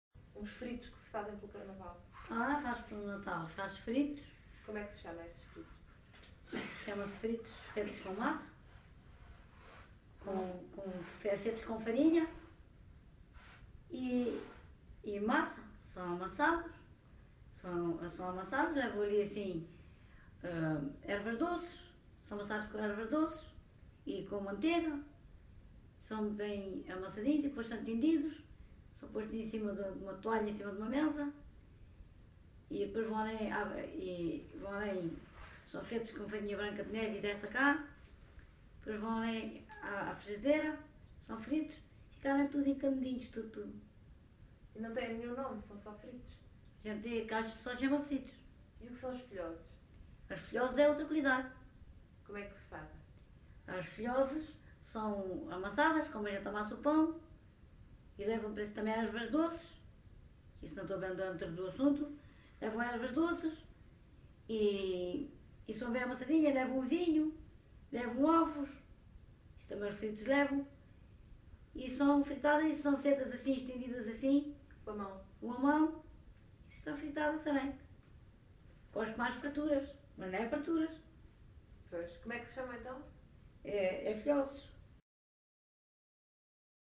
LocalidadeMelides (Grândola, Setúbal)